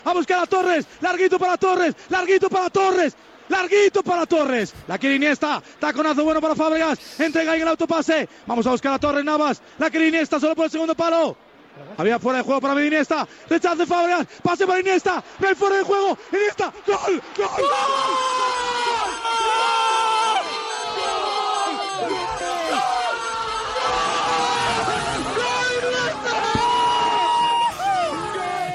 Transmissió de la final de la Copa del Món de futbol masculí entre les seleccions d'Espanya i Països Baixos al Soccer City de Johannesburg, Sud-àfrica.
Narració del gol d'Andrés Iniesta.
Esportiu